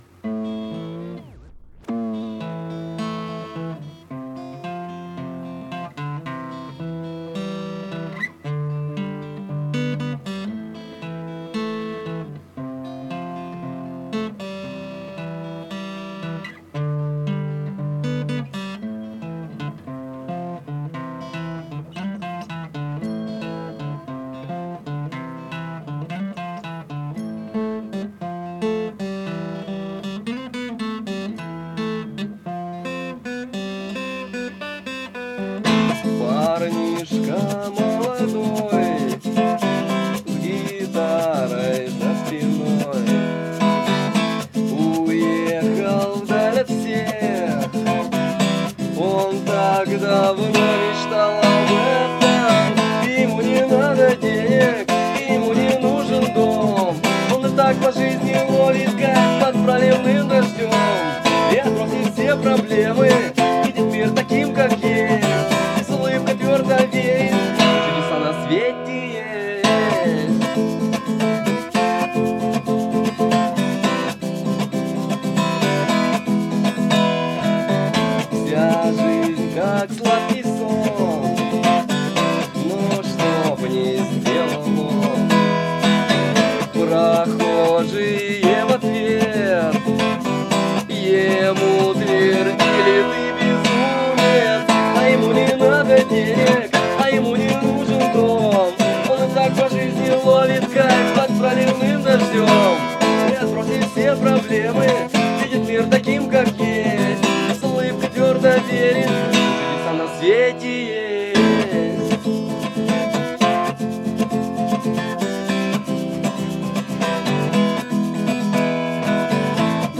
2009 - На репетиции Абсурда 13.03.09 (live)
• Жанр: Бардрок